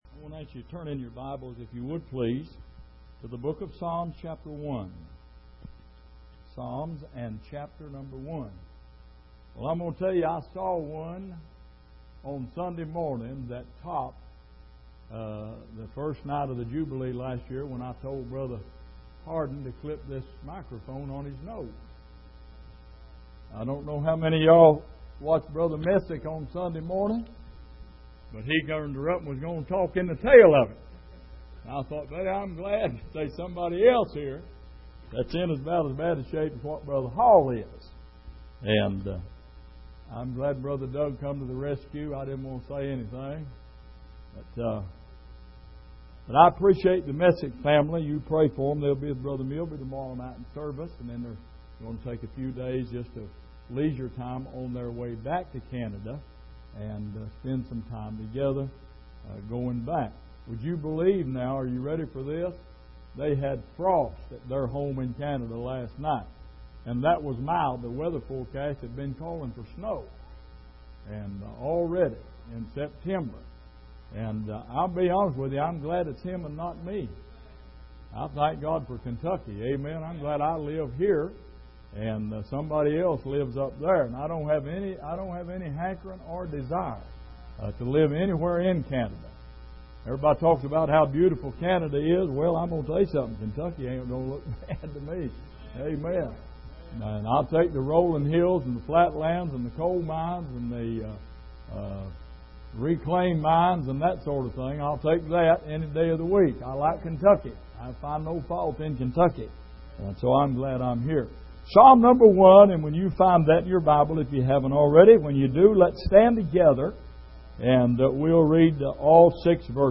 Passage: Psalm 1:1-6 Service: Midweek